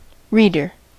Ääntäminen
IPA: [ɑ̃.tɔ.lɔ.ʒi]